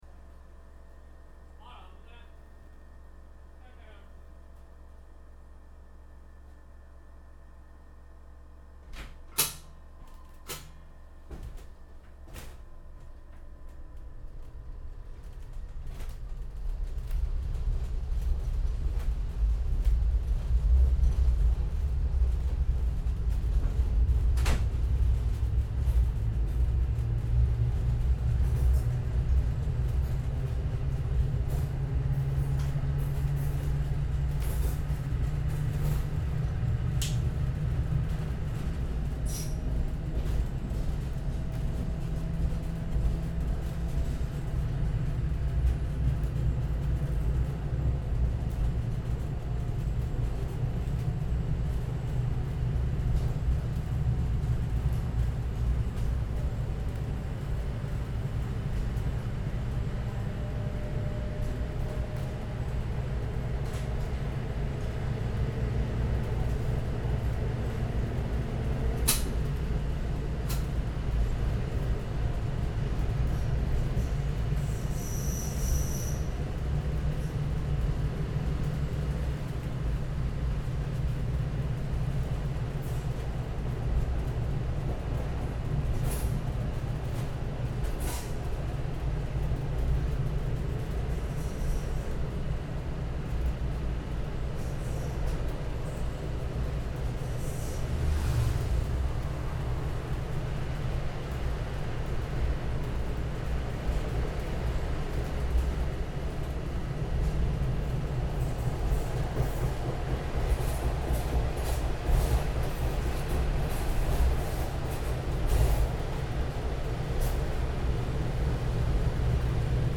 Saltsjöbanan, train from 1912 (2)